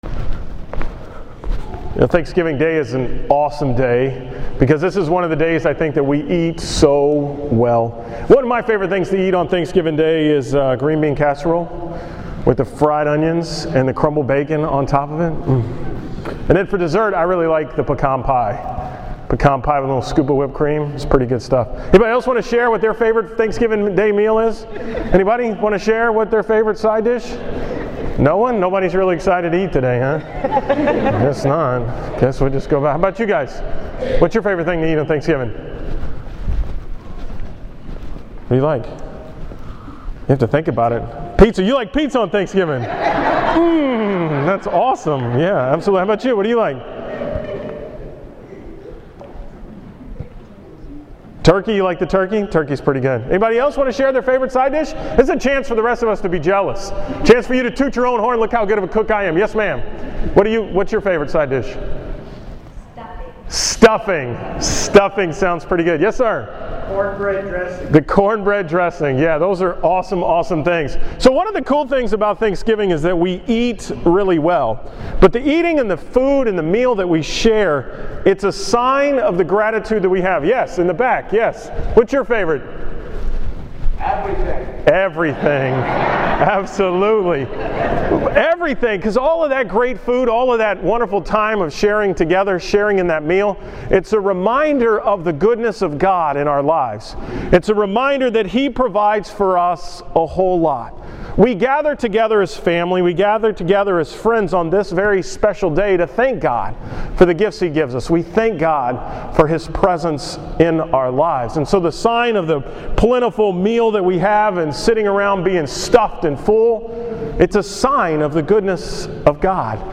From the Mass on Thanksgiving Day 2013
Category: 2013 Homilies